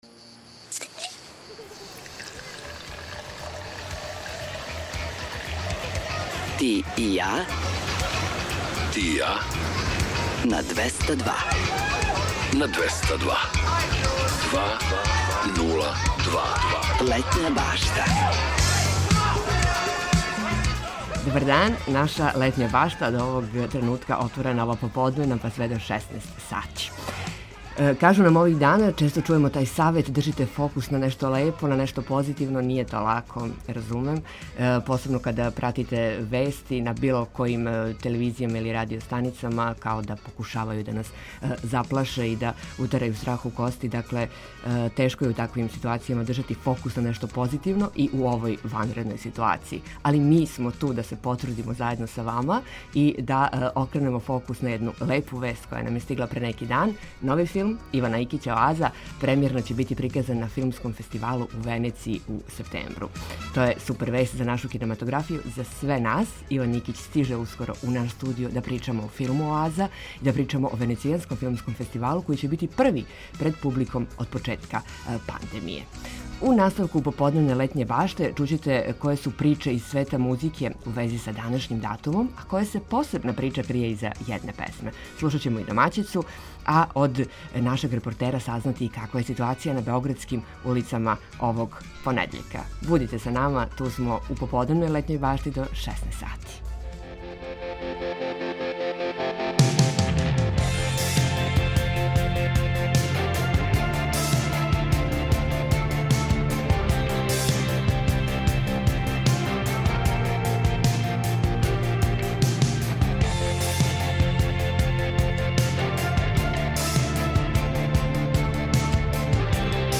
У наставку емисије чућете које су приче из света музике у вези са данашњим датумом, а која се посебна прича крије иза једне песме, слушаћемо и домаћицу, а од нашег репортера сазнати и каква је ситуација на београдским улицама овог понедељка.